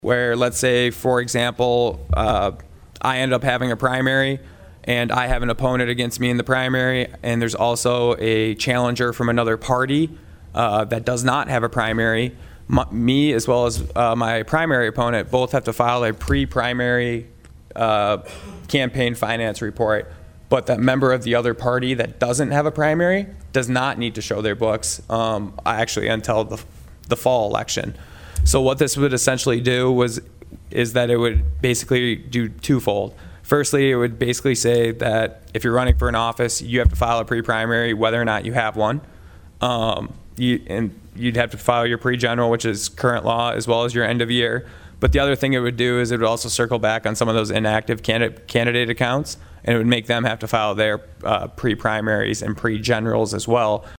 PIERRE, S.D.(HubCityRadio)- District 1 Senator Michael Rohl presented SB17 to the South Dakota Senate State Affairs Committee on Wednesday.
Rohl states an example.